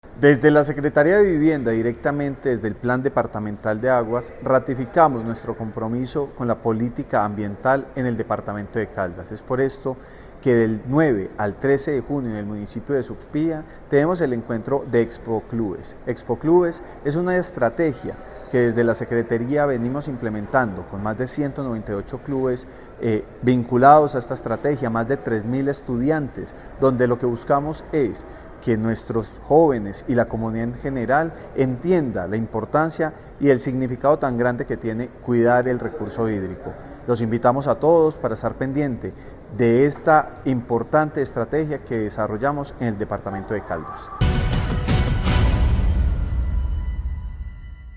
Francisco Javier Vélez Quiroga, secretario de Vivienda de Caldas.